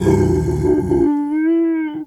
bear_pain_whimper_03.wav